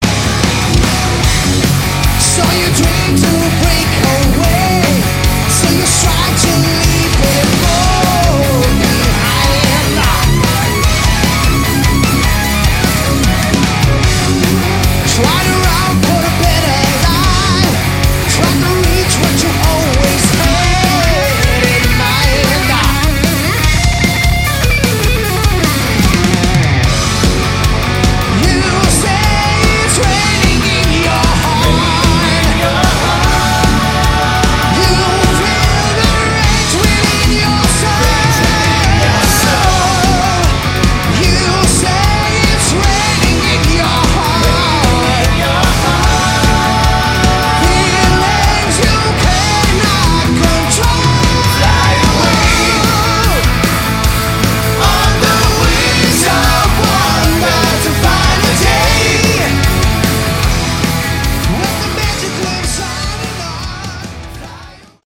Category: Hard Rock
lead vocals
guitars
bass, vocals
keys, vocals
drums